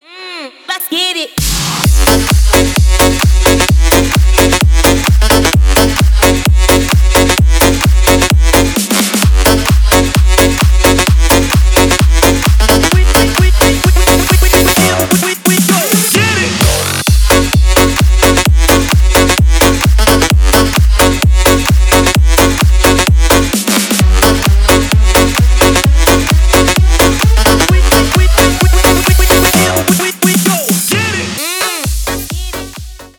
• Качество: 320 kbps, Stereo
Танцевальные
клубные
громкие